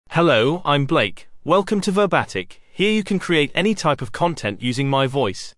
MaleEnglish (United Kingdom)
Blake is a male AI voice for English (United Kingdom).
Voice sample
Blake delivers clear pronunciation with authentic United Kingdom English intonation, making your content sound professionally produced.